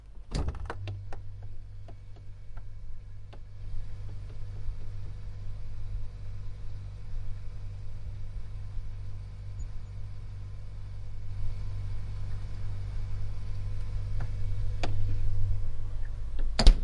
家庭和酒店卷 1 " 厨房，冰箱冷藏室，冷冻室关闭
描述：关闭冰箱/冰柜的冷冻室。
标签： 厨房 冰箱 轰的一声 家里 关闭 幻灯片 冰柜 冰箱 家用
声道立体声